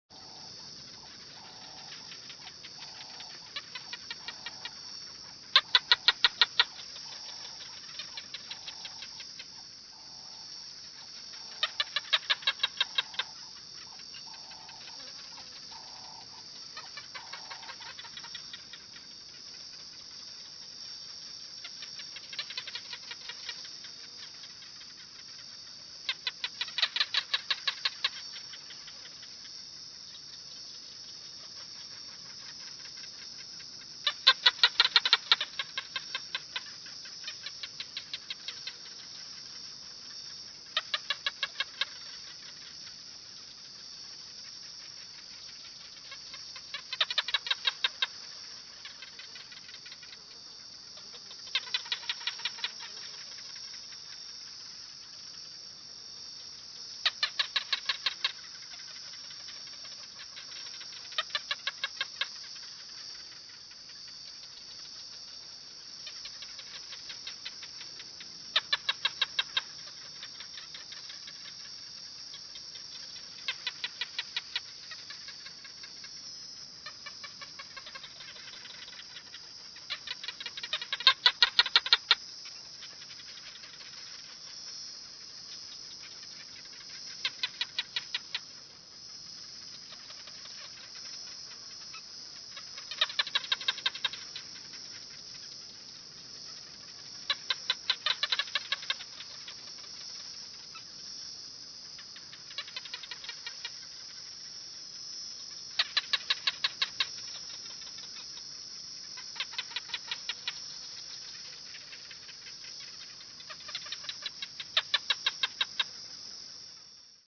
На этой странице собраны разнообразные звуки ящериц: от мягкого шуршания чешуи до резкого шипения.
Звук африканских гекконов